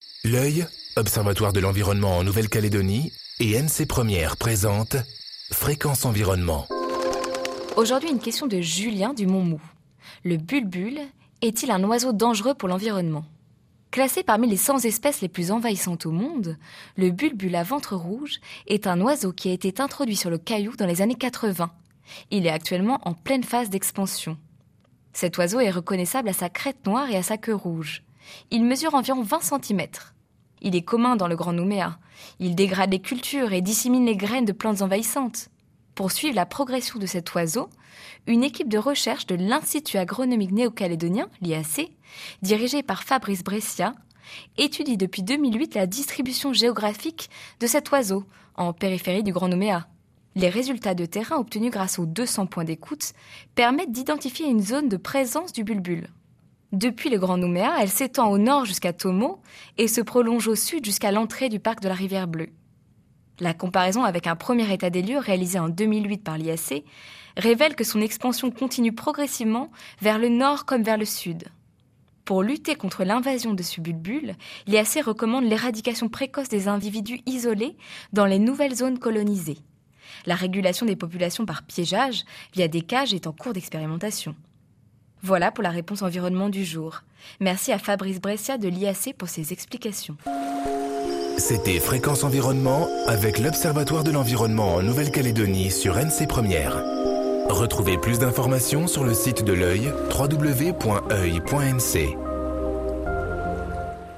diffusée en janvier 2014 sur NC 1ère